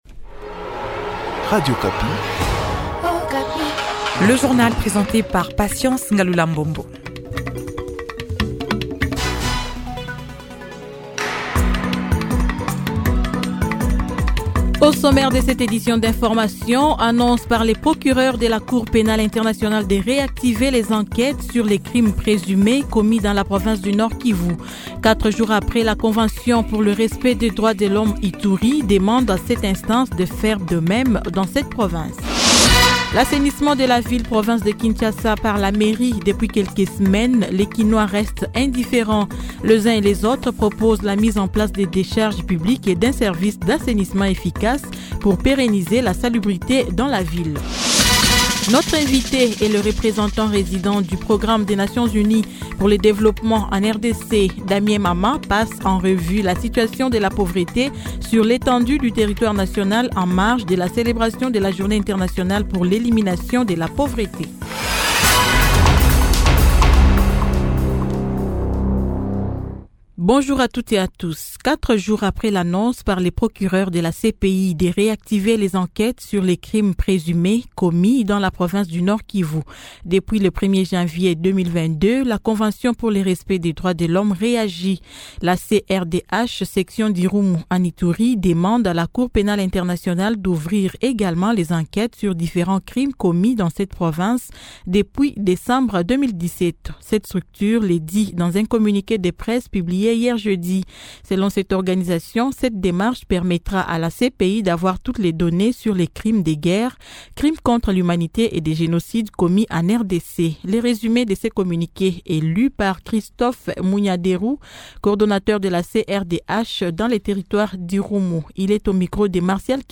Journal matin 08H00